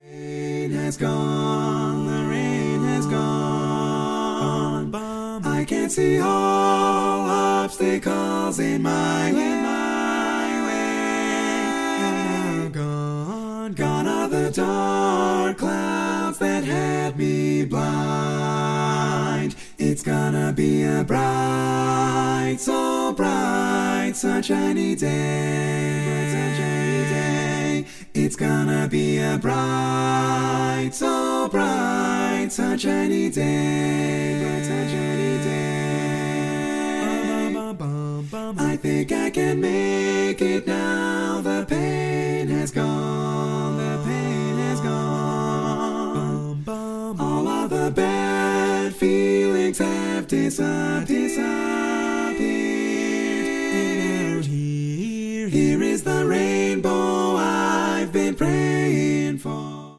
Full mix only
Category: Female